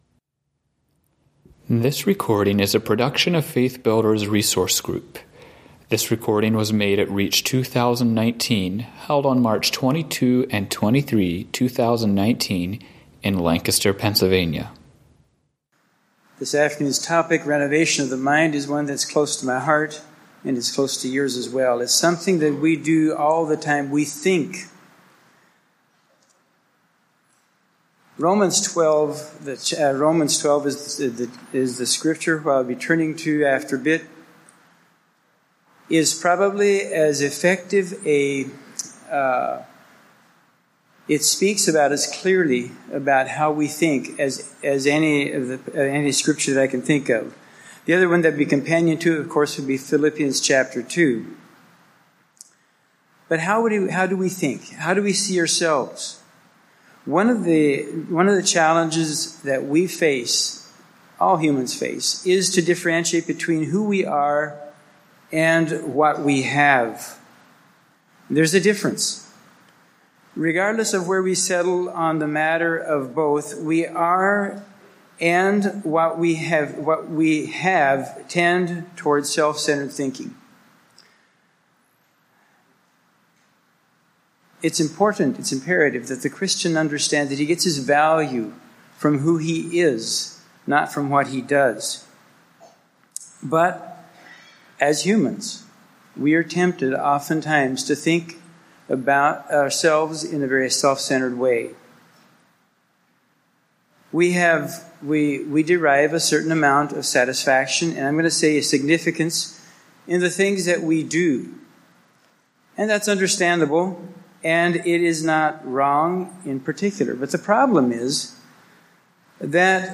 Home » Lectures » Renovation of the Mind